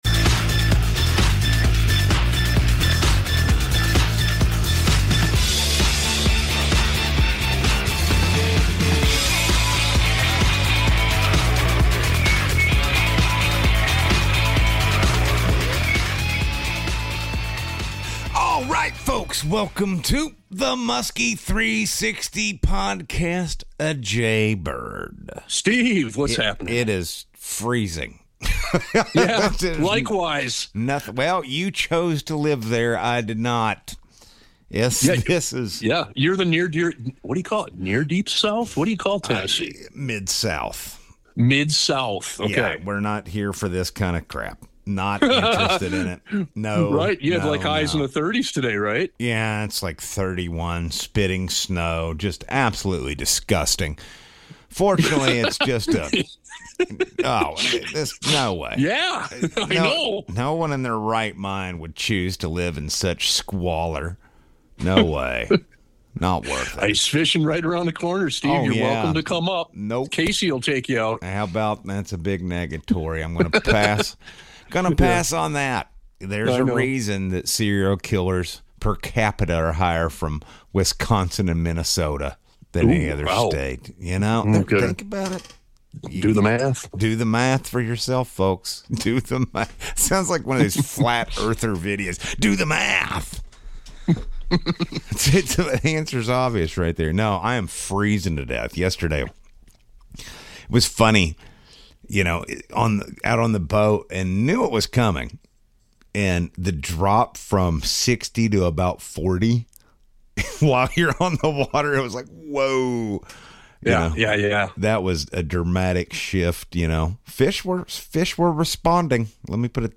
Learn how to prepare your gear and tackle for freezing conditions, the best presentation tactics for both artificial lures and live bait , and how to stay effective when the bite gets tough. Plus, the guys answer listener questions in an all-new Musky 360 Q&A session , covering real-world tactics and late-season strategies that keep you on fish until the ice sets in.